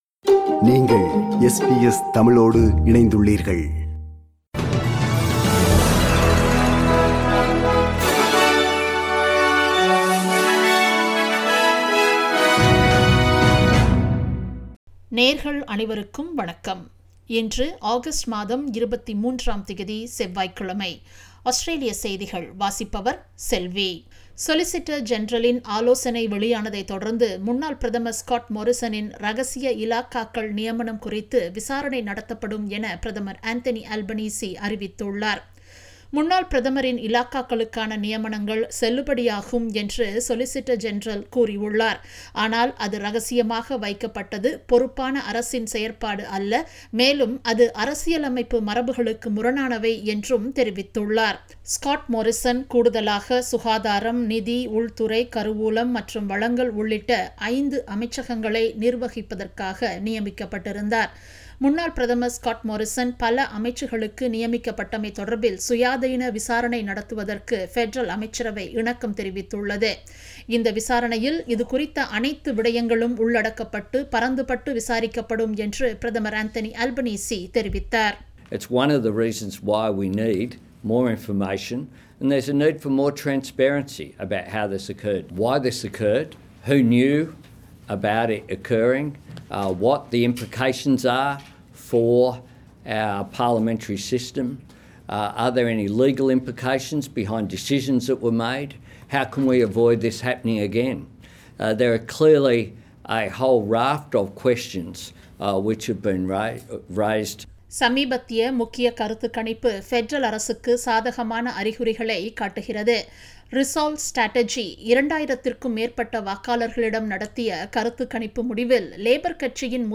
Australian news bulletin for Tuesday 23 Aug 2022.